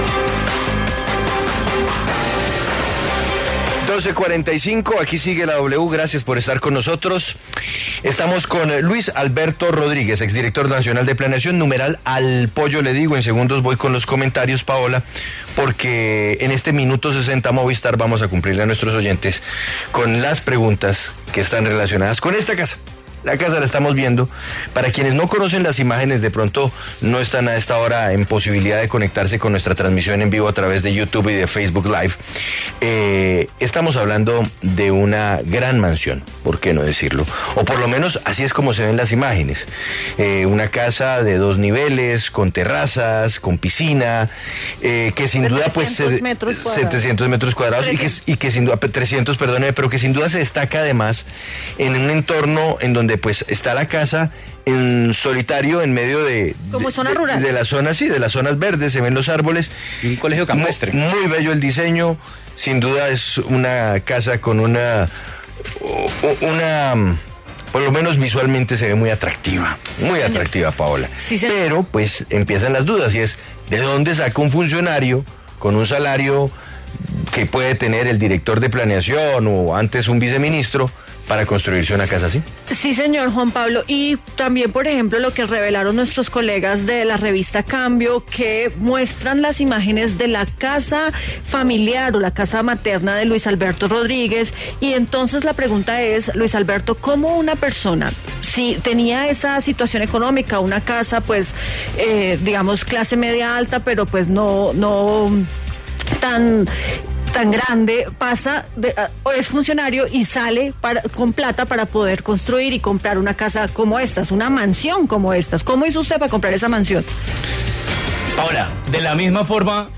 Luis Alberto Rodríguez, exdirector del Departamento Nacional de Planeación, conversó en exclusiva para Sigue La W a propósito de las críticas que ha recibido por la casa que construyó en Valledupar.